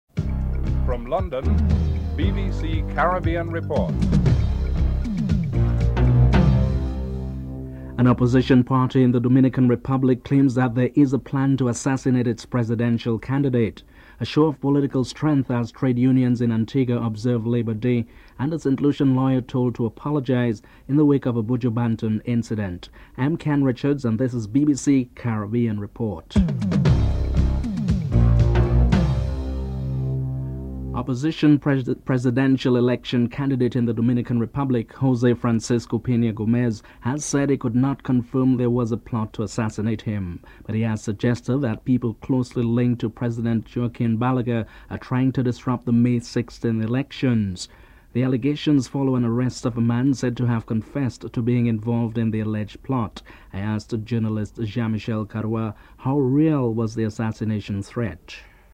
1. Headlines (00:00-00:30)